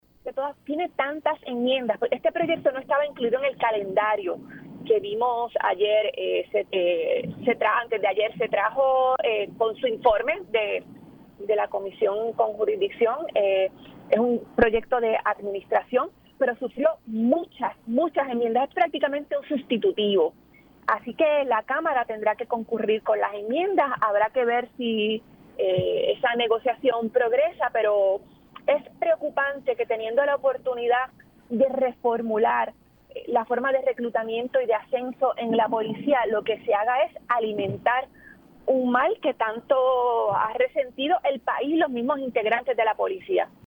317-MARIA-DE-LOURDES-SANTIAGO-SENADORA-PIP-CAMARA-DEBE-COINCIDIR-CON-ENMIENDAS-A-PROYECTO-DE-LA-POLICIA.mp3